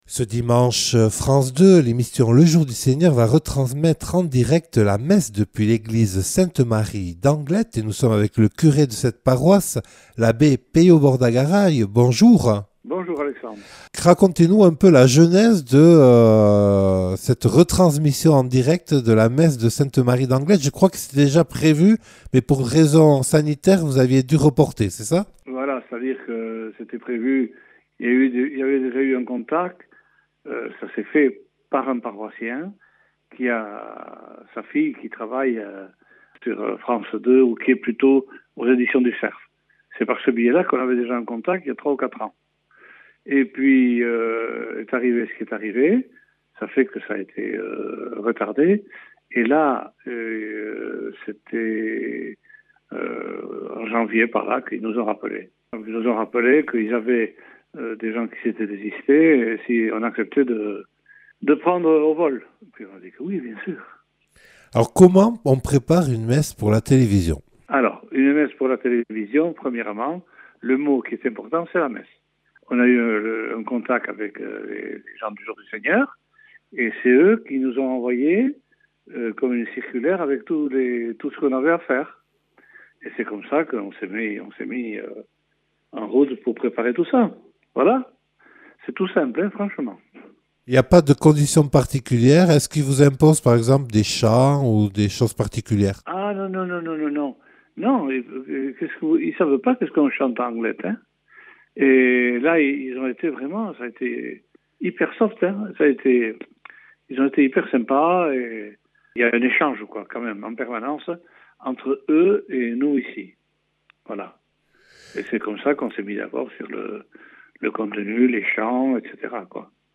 08H00 | Interviews et reportages